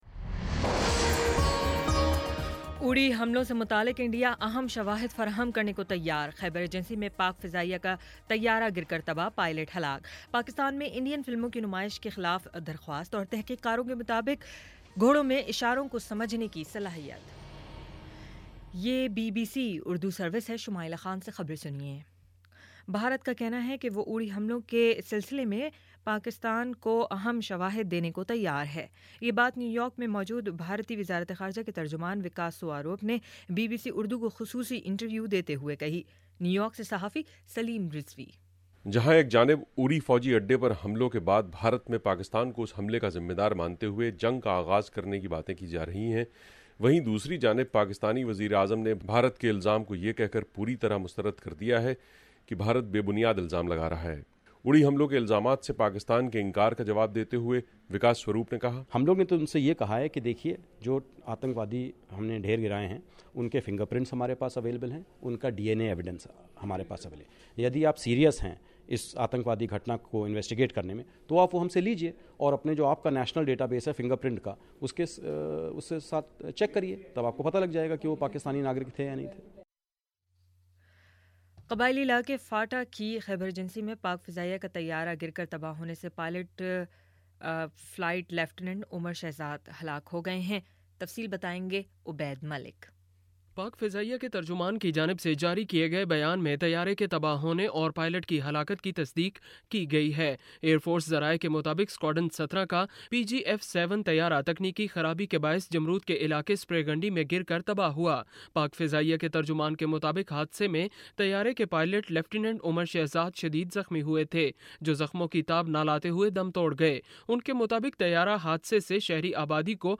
ستمبر 24 : شام پانچ بجے کا نیوز بُلیٹن